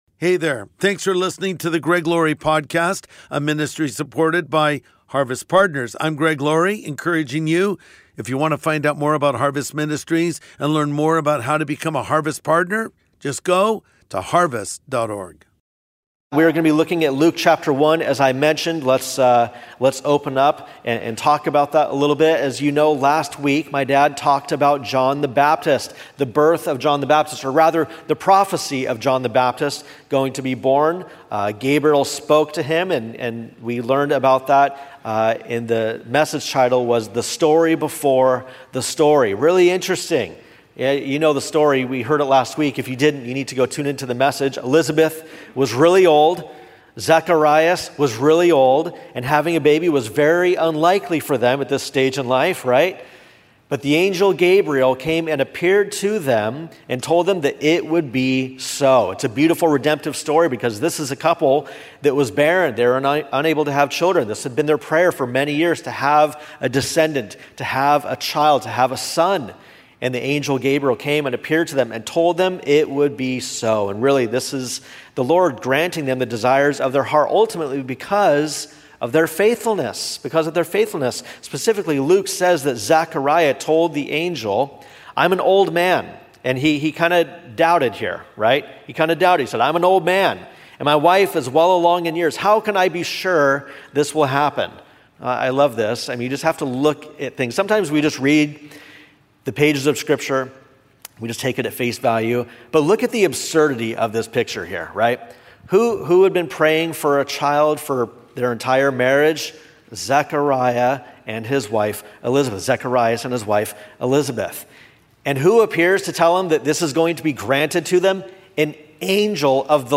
When the Time Was Just Right | Sunday Message